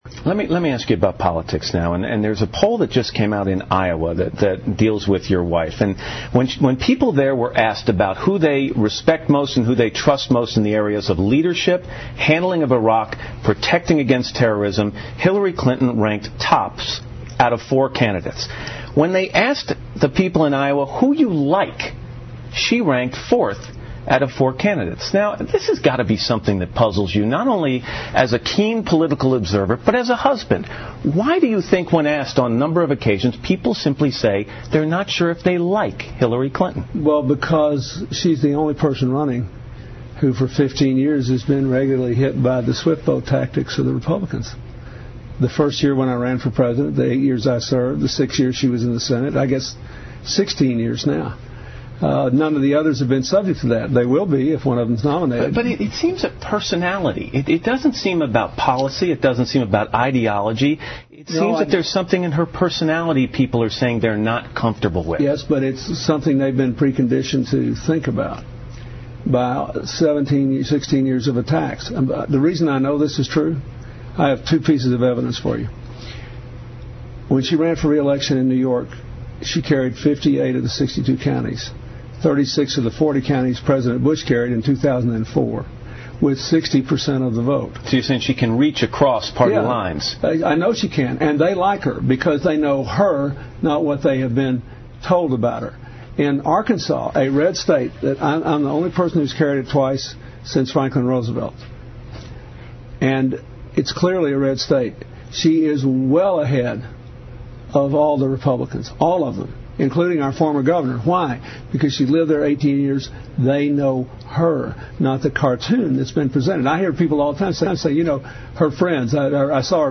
访谈录 Interview 2007-09-11&09-12, 克林顿：权利依旧（下） 听力文件下载—在线英语听力室